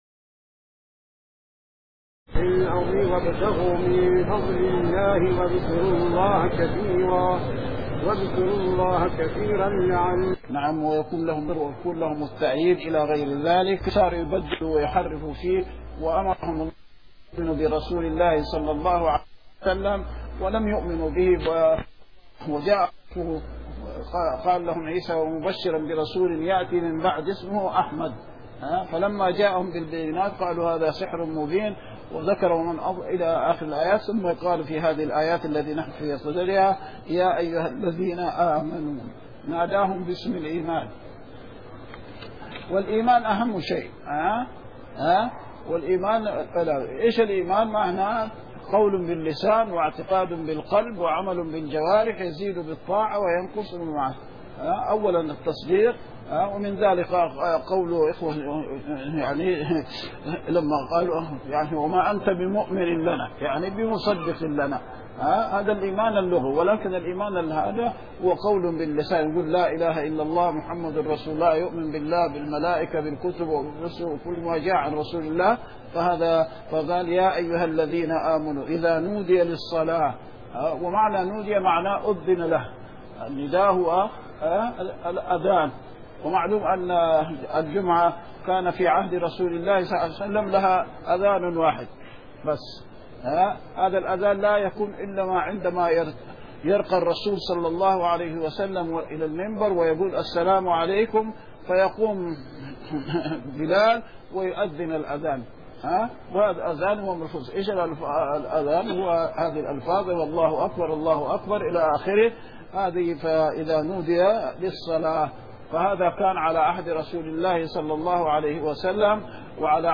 دروس الحرم المدنى الشريف تفسير الآيات 1-8 من سورة الجمعة